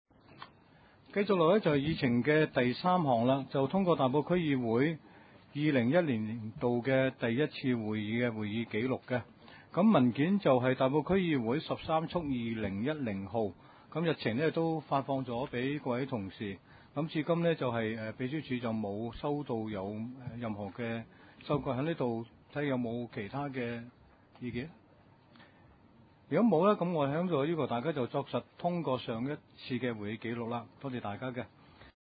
2010年度第二次會議
地點：大埔區議會秘書處會議室